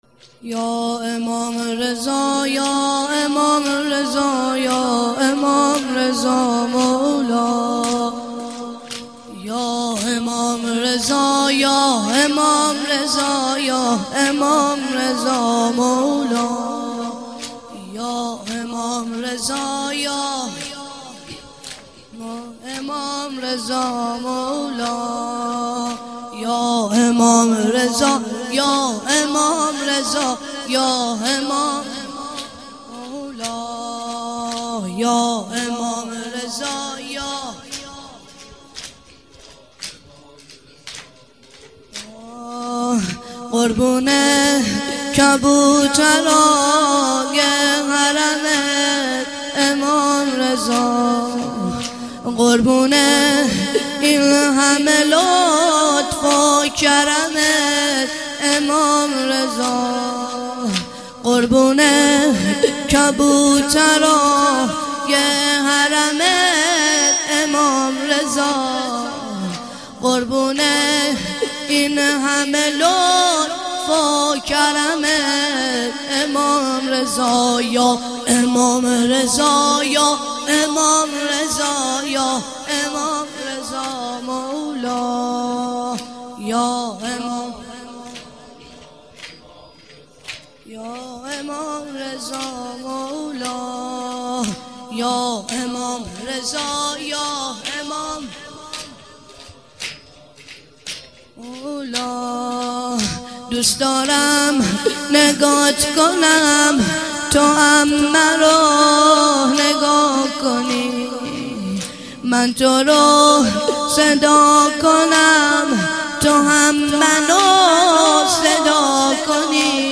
یا امام رضا - مداح